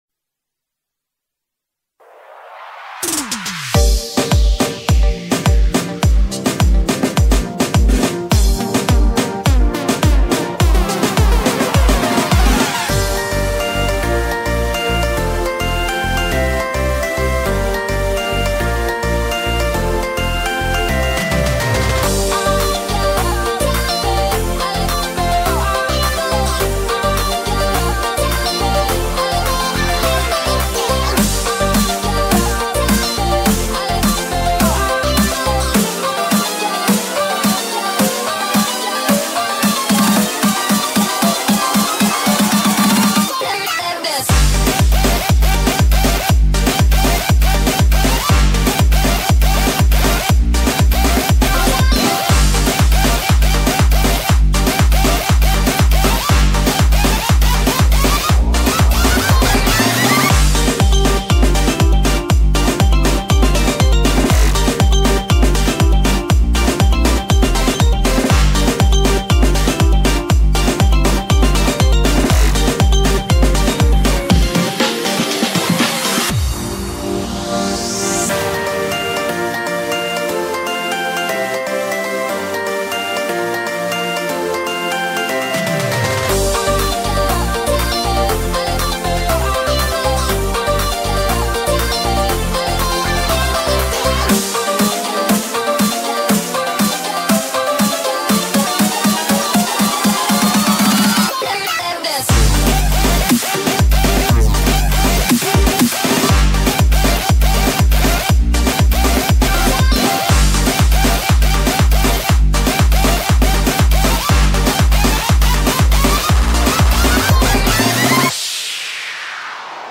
BPM105-120
Audio QualityPerfect (High Quality)
- BPM changes in gradual speedup fixed.